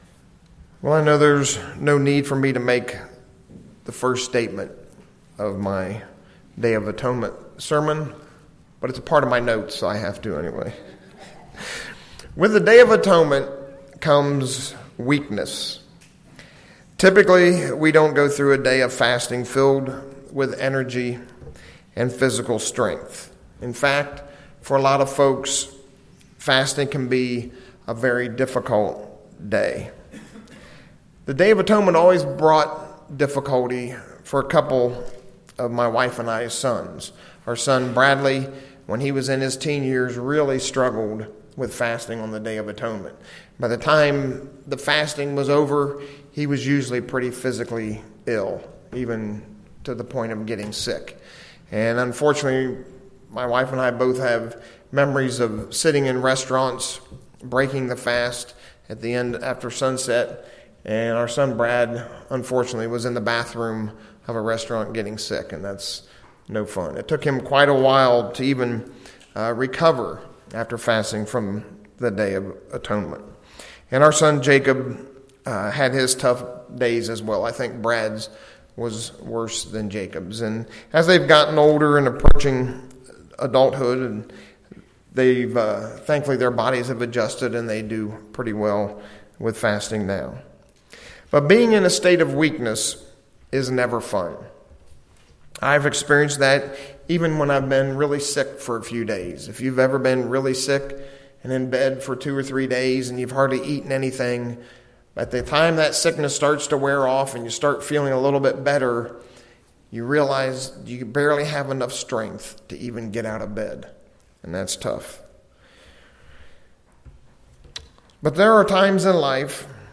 We as humans, the weakest of all powers, are caught in the middle of two great plans - God's and Satan's. This sermon looks at this war between good and evil and the plan that leads a christian to success as we stand in the middle of this very powerful battle for eternal life.